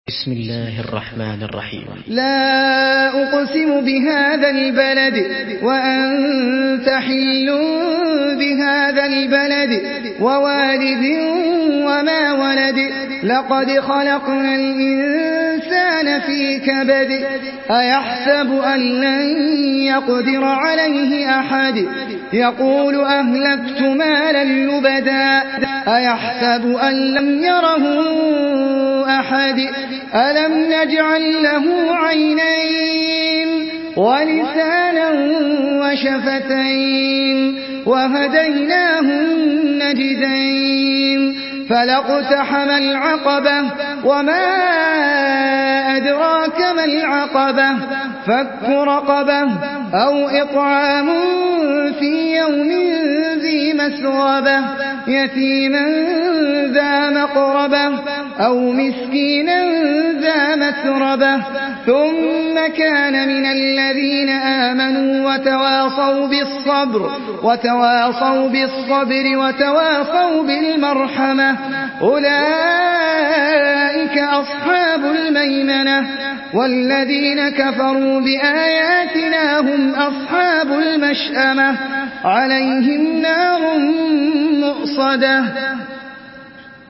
Surah আল-বালাদ MP3 by Ahmed Al Ajmi in Hafs An Asim narration.
Murattal Hafs An Asim